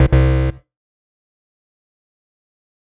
错误.mp3